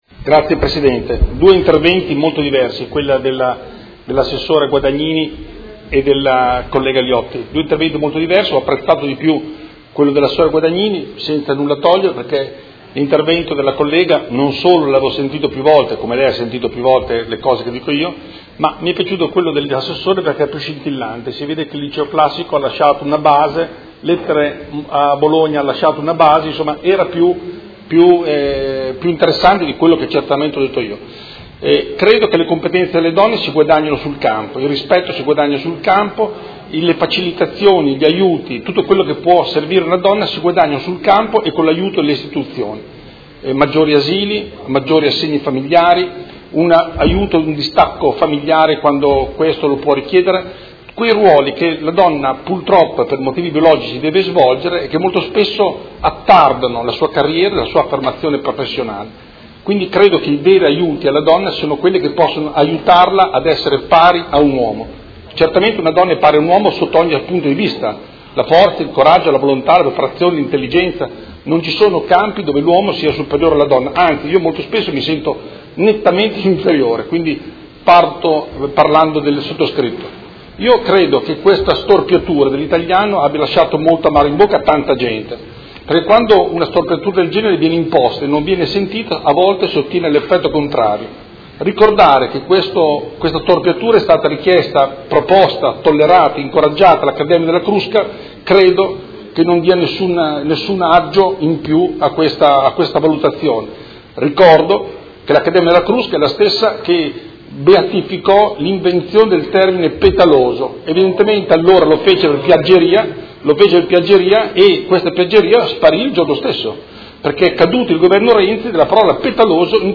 Seduta de 29/11/2018 Dibattito. Interrogazione del Consigliere Galli (F.I) avente per oggetto: Linguaggio di genere.